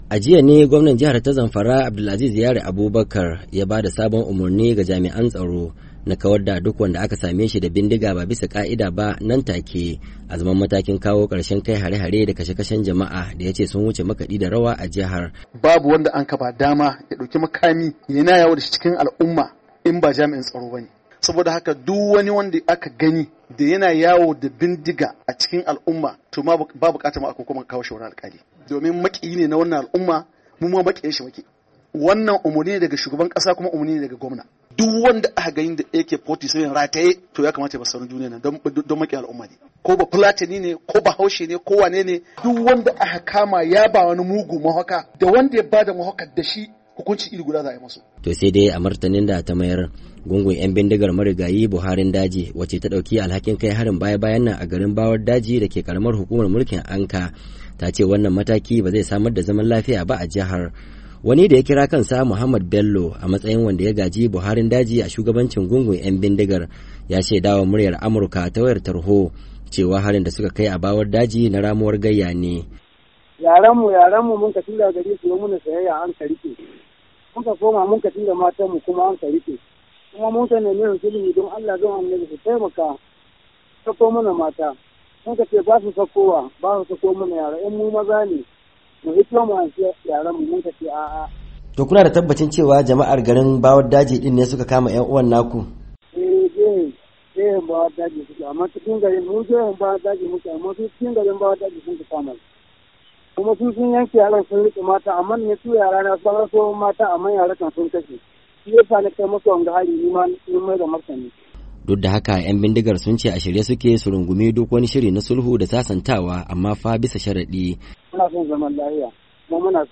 Amma da Muryar Amurka ta tuntubi gwamnan jihar Abdul’aziz Yaro, ya ce su kam a yanzu sun cimma matsayar sanya kafar wando daya ne kawai da maharan, saboda gazawar matakin sulhu da aka dauka a can baya.